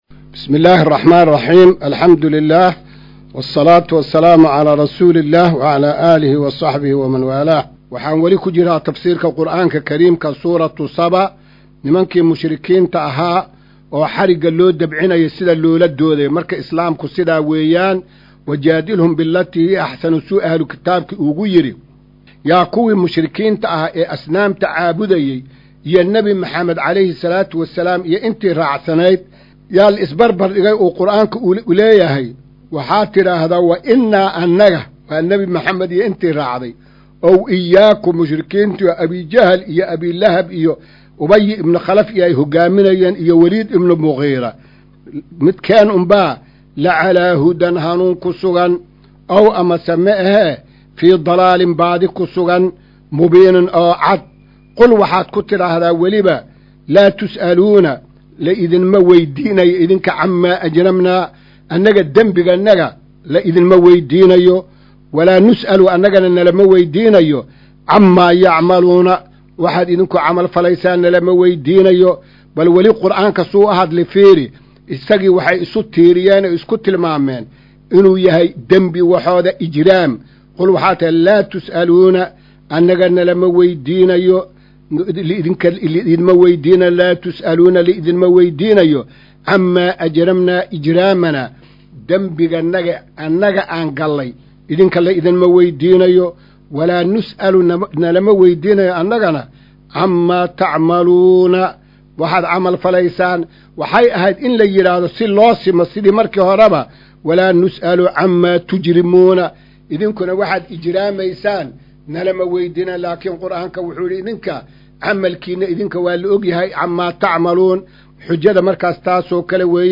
Maqal:- Casharka Tafsiirka Qur’aanka Idaacadda Himilo “Darsiga 204aad”